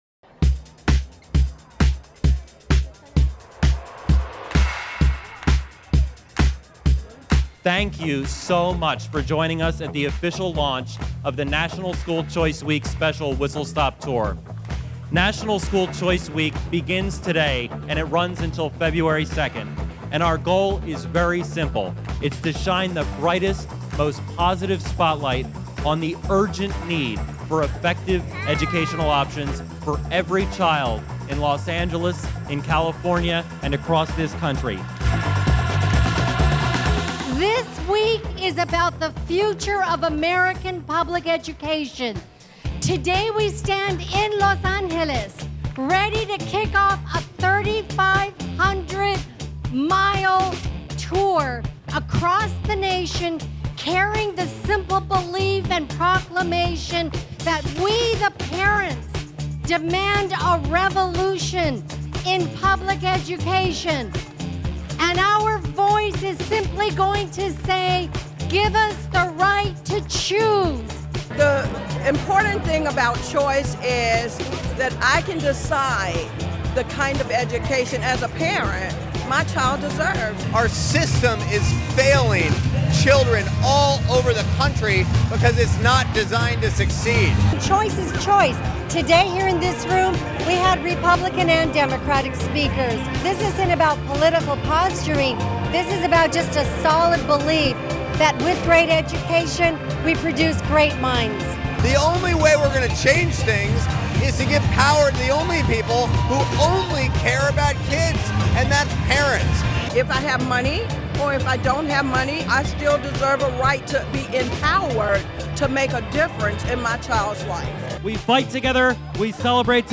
Hundreds of students and their parents helped kick off National School Choice Week 2013, January 26, at Union Station in Los Angeles, Calif.